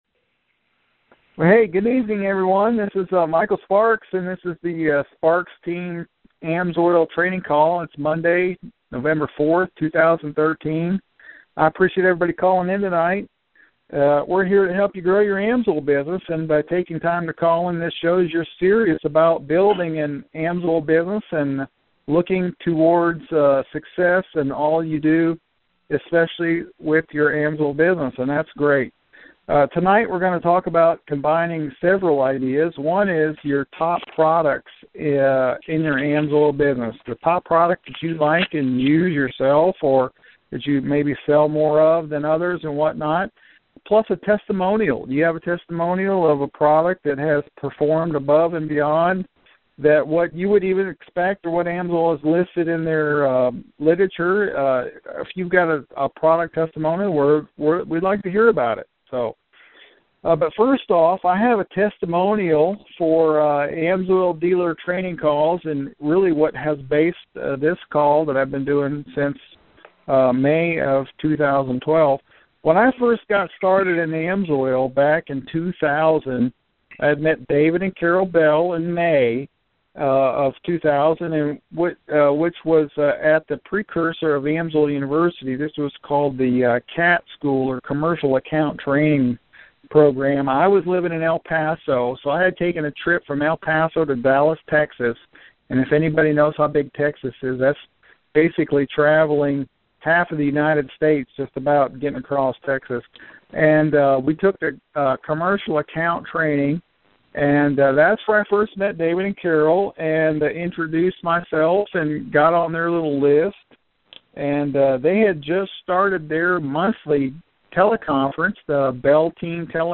On this call you will hear several AMSOIL dealers list their top 3 products as well as a great testimonial on a product they use.